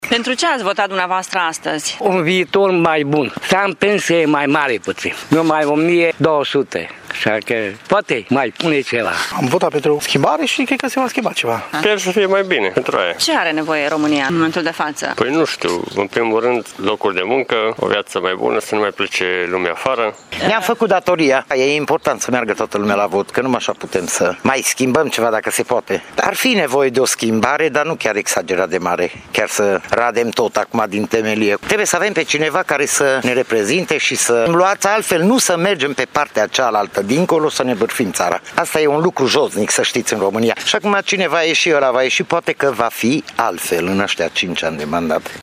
Pensii mai mari, salarii mai bune, locuri de muncă și unitate au nevoie românii în momentul de față, spun alegătorii târgumureșeni care au votat azi.